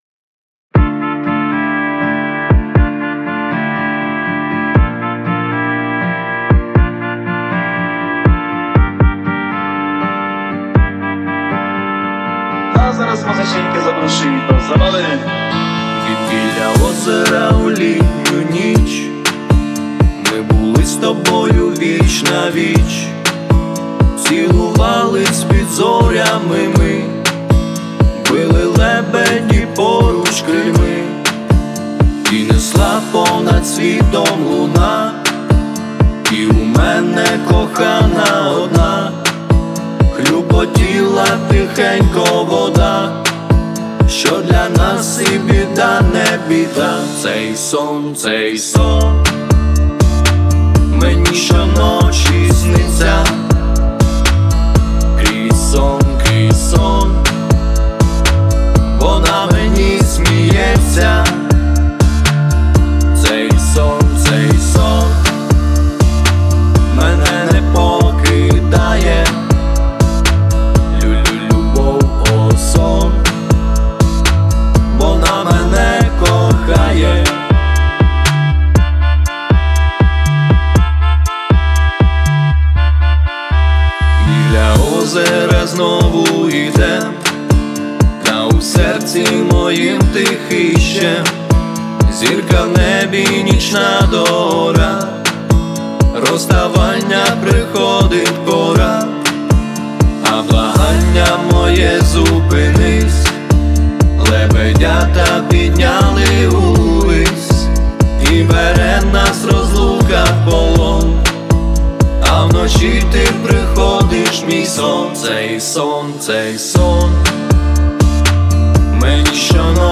Плюсовий запис